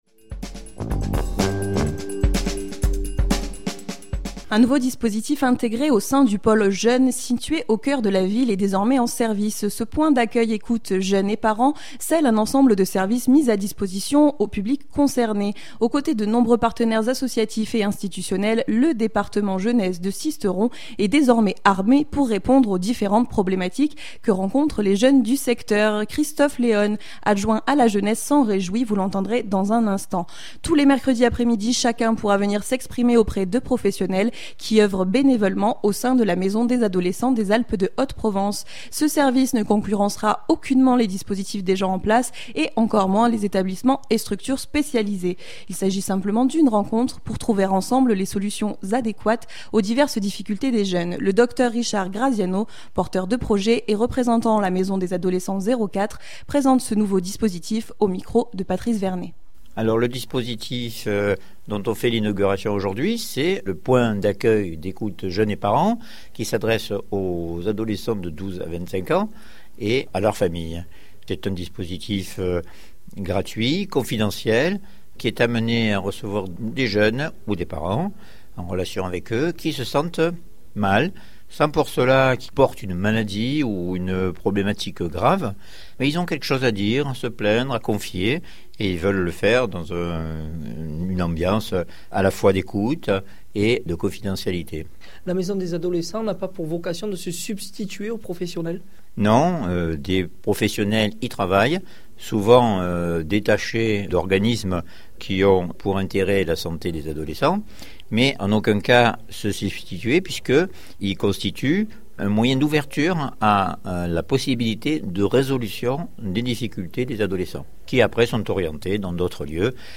Christophe Léone, Adjoint à la jeunesse s’en réjouit, vous l’entendrez dans un instant. Tous les mercredis après-midi, chacun pourra venir s’exprimer auprès de professionnels qui œuvrent bénévolement au sein de la maison des adolescents des Alpes de Haute Provence.